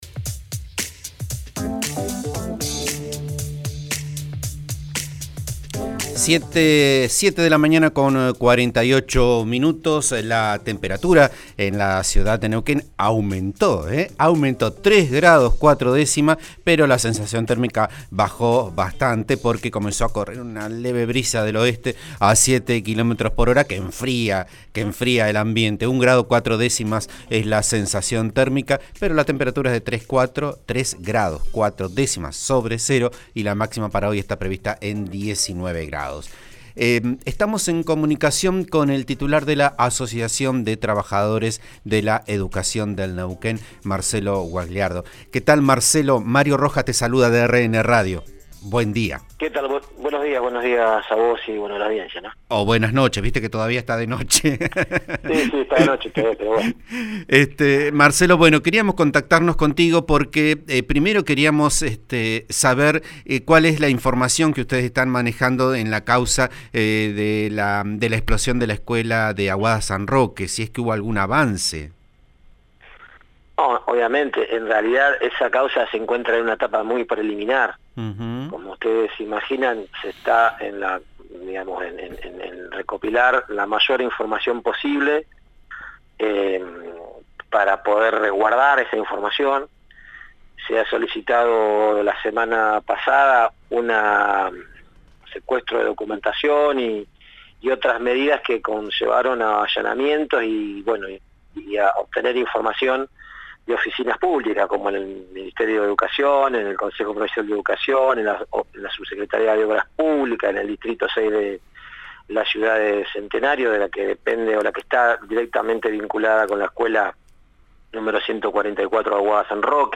declaraciones al programa Vos a Diario, de RN RADIO (89.3)